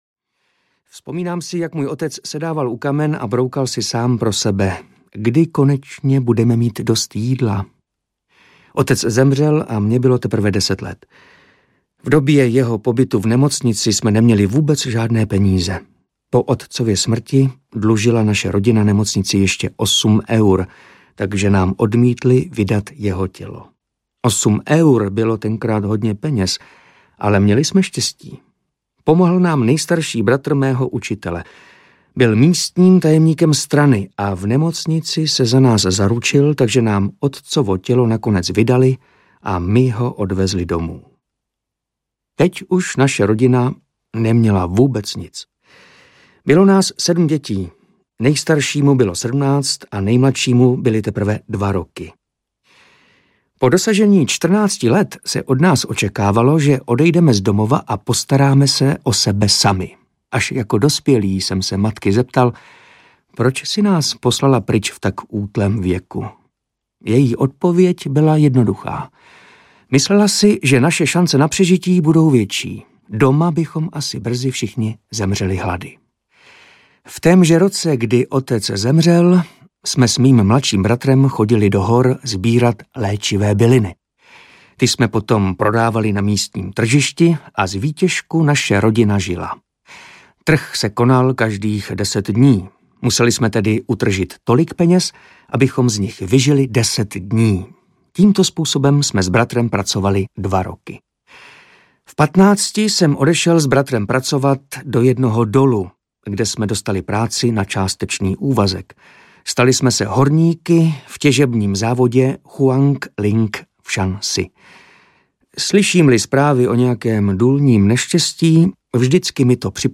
Za Čínu spravedlivější audiokniha
Ukázka z knihy
• InterpretMartin Myšička